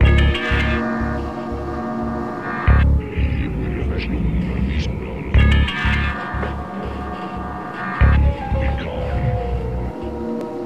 描述：+磬琴+钢琴+鬼怪
Tag: 90 bpm Ambient Loops Synth Loops 1.79 MB wav Key : Unknown